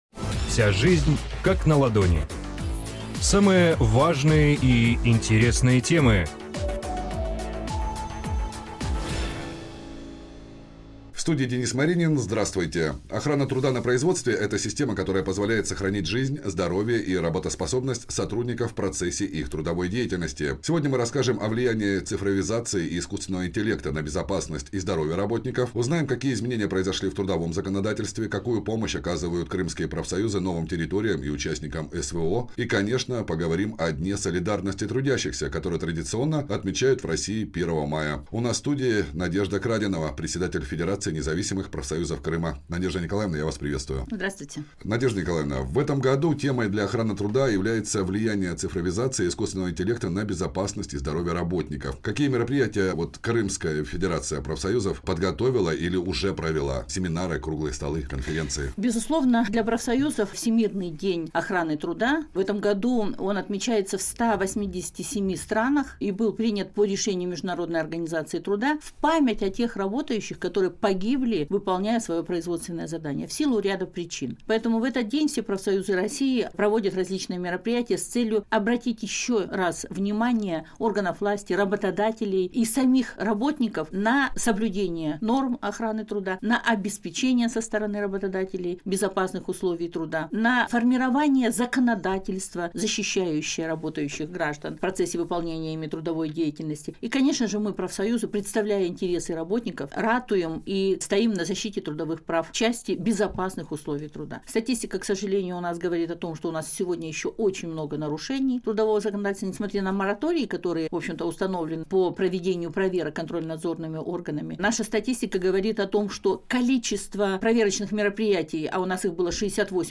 У нас в студии